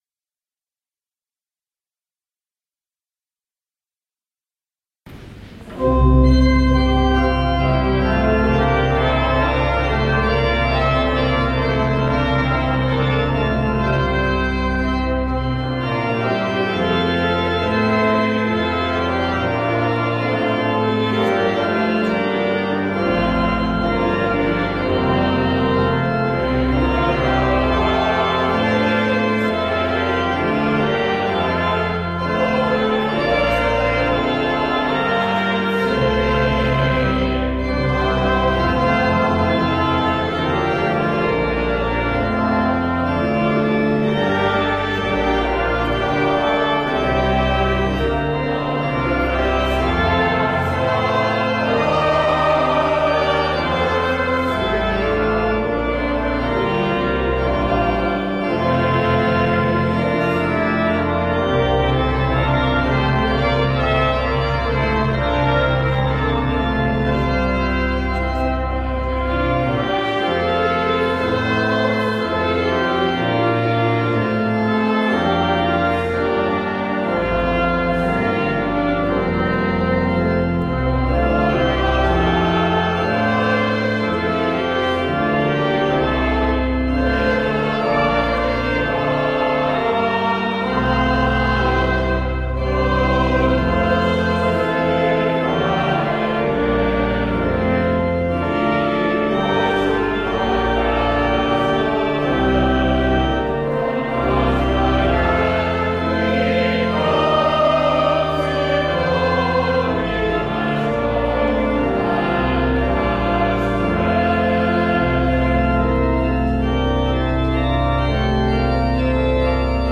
A recording from the rehearsal the day before the Festival is included here. [Please note that this recording was made on a small device placed on the floor about 1/2 way down the main aisle. Sound fidelity is not perfect. The actual sound of the group, mostly organists and choir directors, was amazing!]
Hymn Festival, April, 2013
Festival Choir